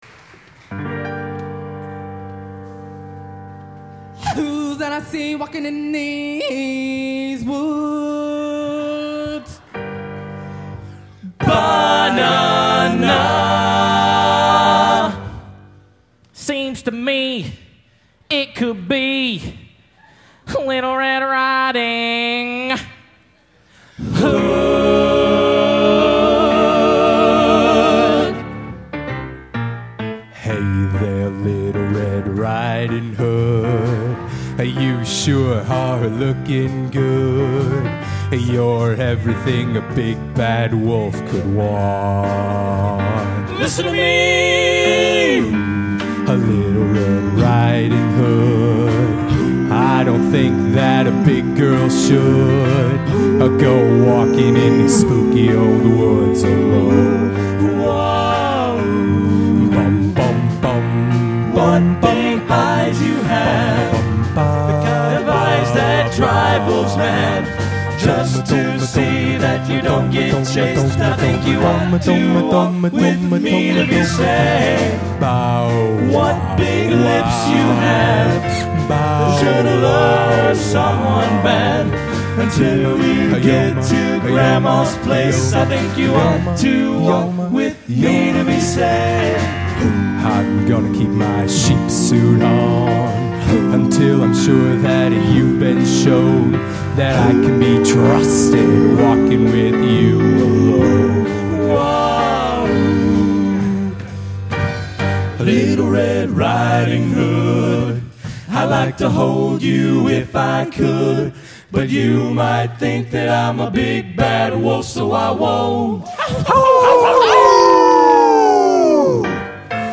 Location: Rossville, Indiana